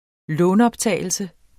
Udtale [ ˈlɔːn- ]